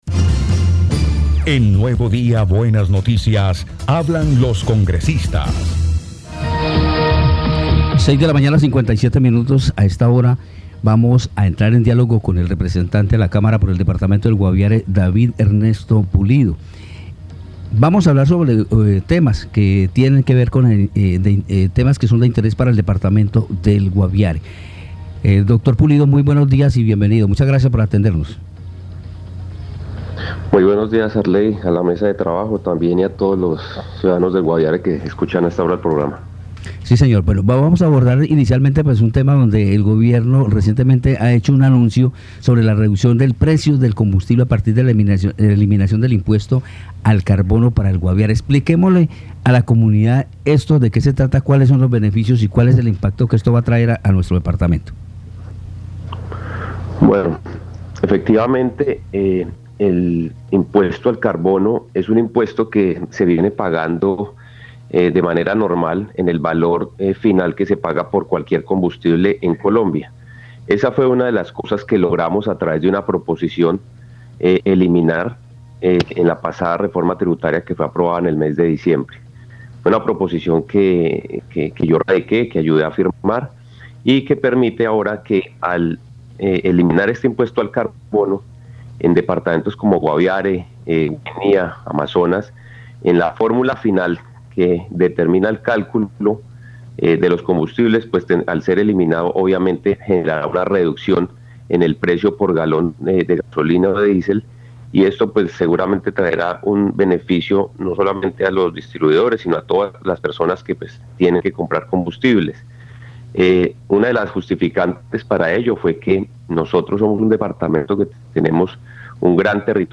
Escuche a David Pulido, representante a la Cámara por el Guaviare.